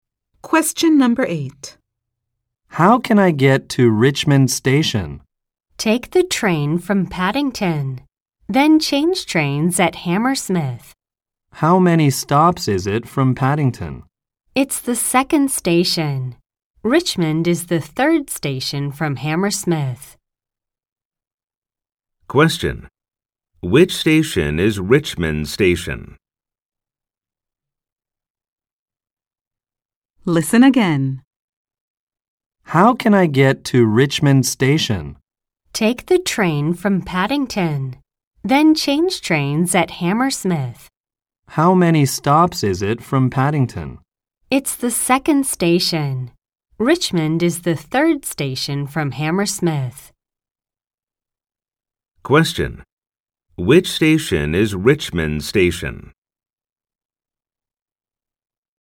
〇アメリカ英語に加えて、イギリス英語、アジア英語の話者の音声も収録しています。
ノーマル・スピード音声   ハイ・スピード音声